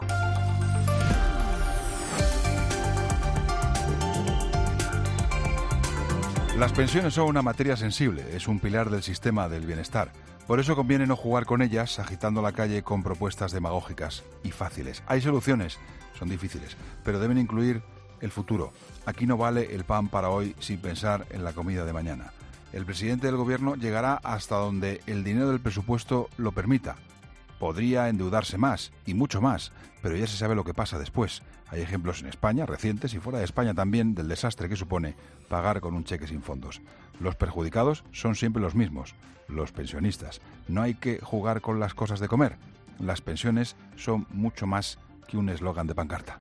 AUDIO: Escucha el análisis del director de 'La Linterna' en 'Herrera en COPE'